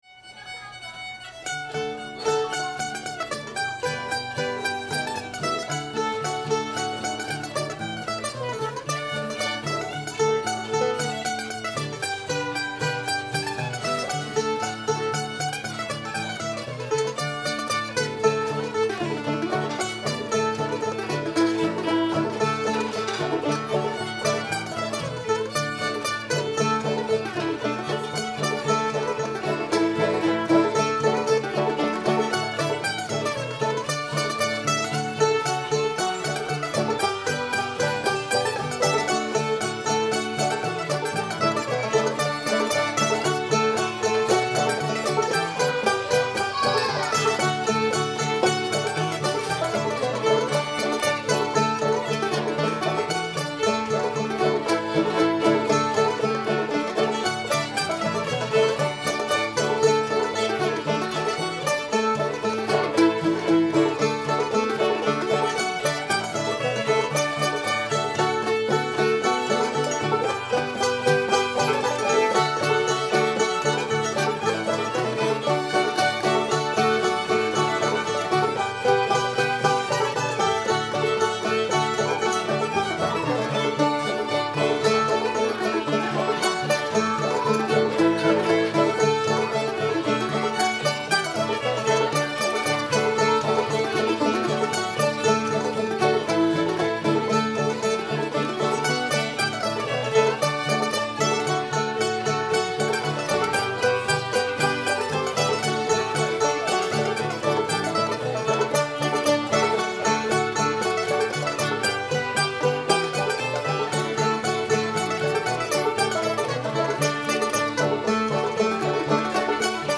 liberty [D]